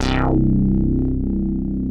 OSCAR 8 C2.wav